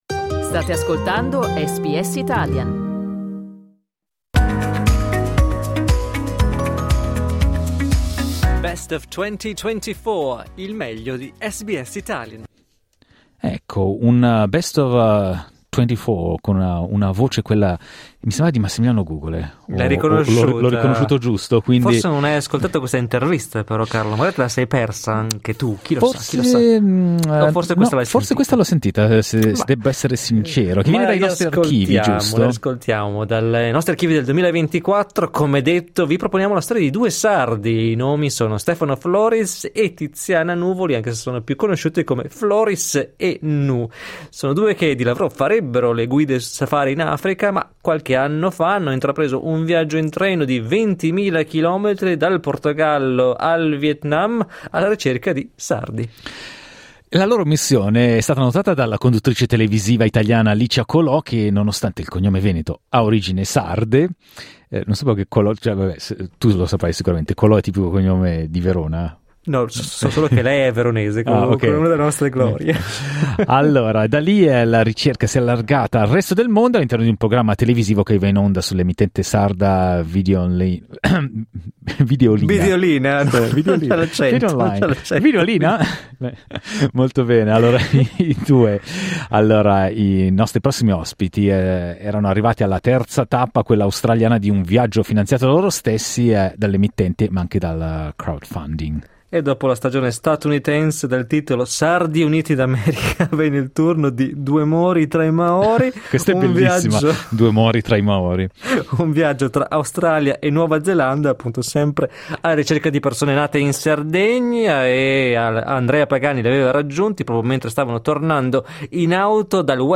Noi li abbiamo intervistati a giugno dell'anno scorso, mentre stavano attraversando l'Australia.